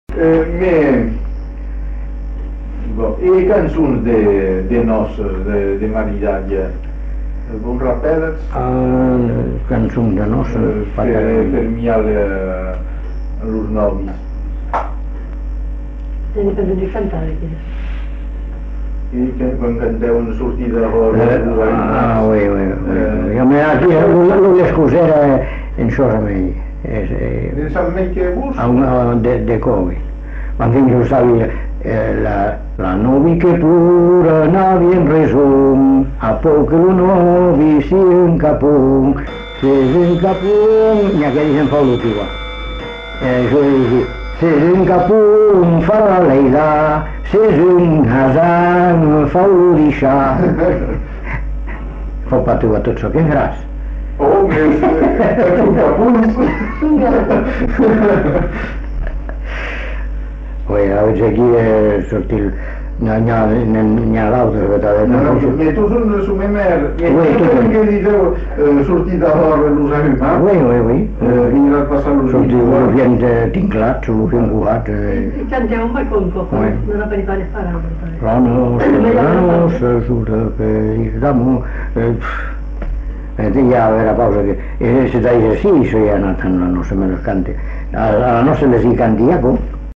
Aire culturelle : Bazadais
Lieu : Bazas
Genre : chant
Effectif : 1
Type de voix : voix d'homme
Production du son : chanté